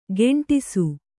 ♪ geṇṭisu